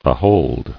[a·hold]